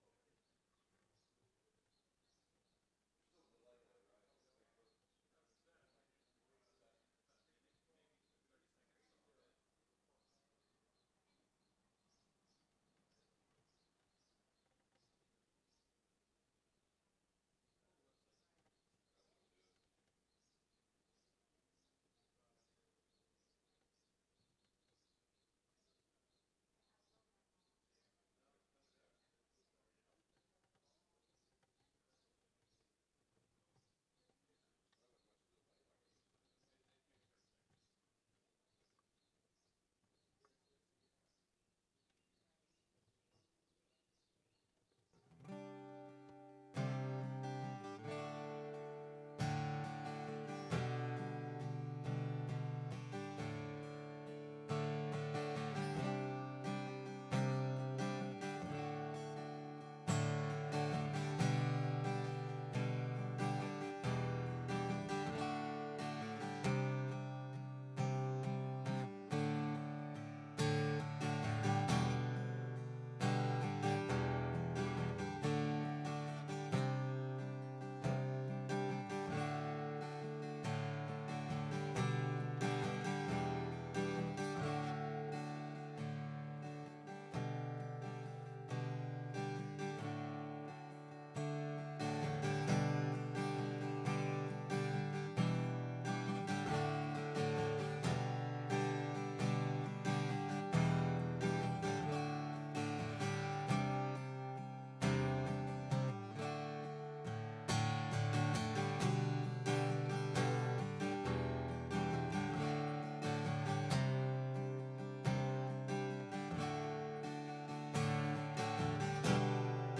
SERMON DESCRIPTION God is a sovereign God who positions His people to be in the right places at the right times to accomplish His purposes!